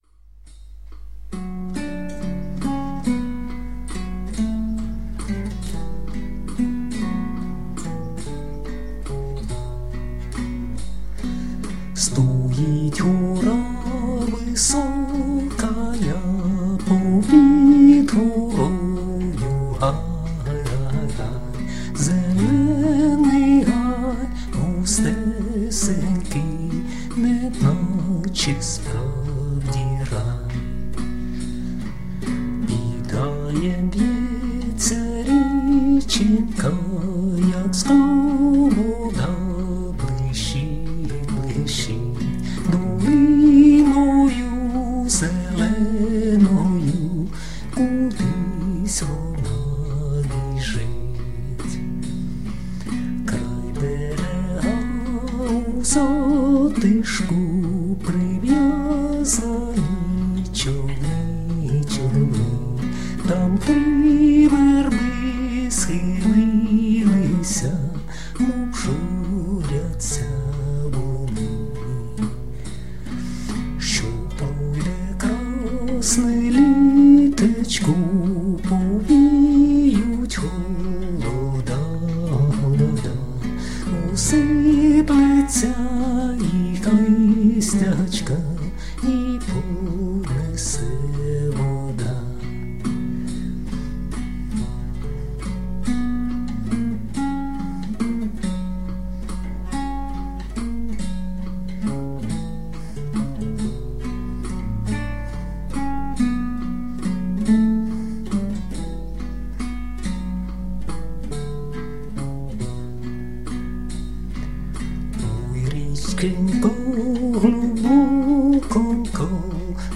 ../icons/sumerki.jpg   Українська народна пісня